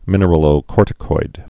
(mĭnər-ə-lō-kôrtĭ-koid)